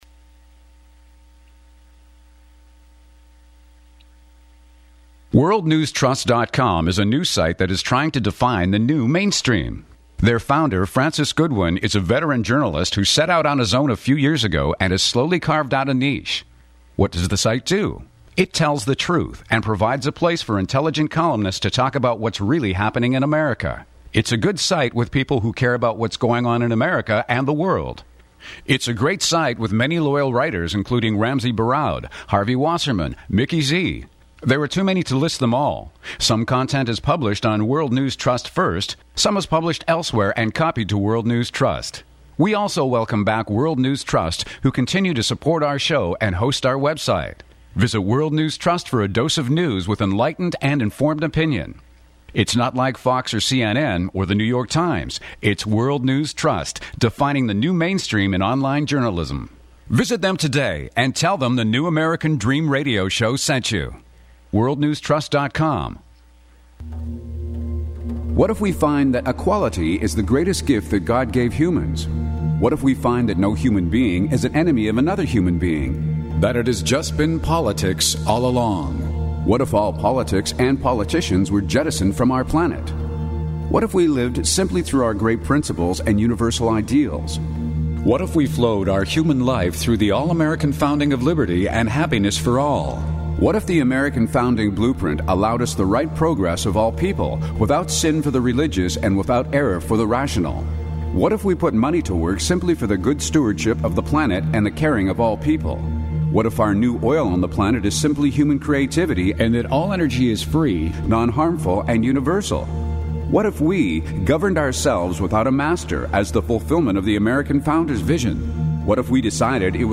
June 21, 2012 | The New American Dream Radio Show